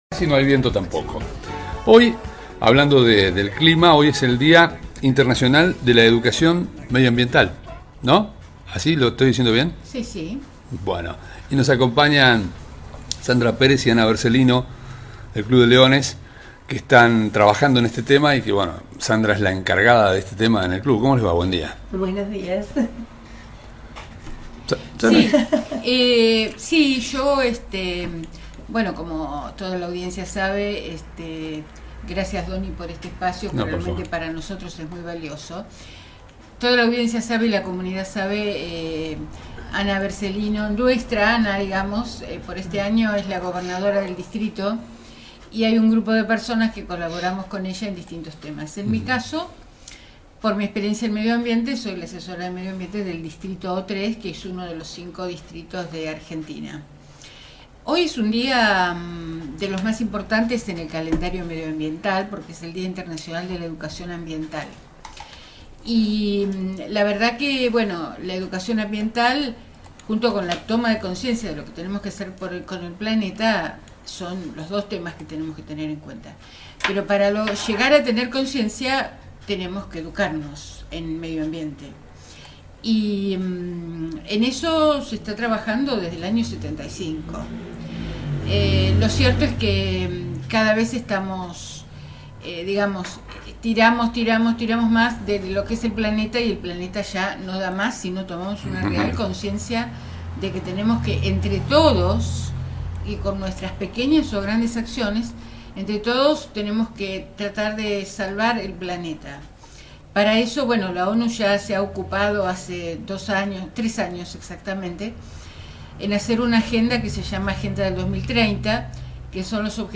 La Gobernadora de Distrito de Leones y la asesora en medioambiente conversaron con nosotros sobre la importancia de la labor educativa para la preservación del medio ambiente.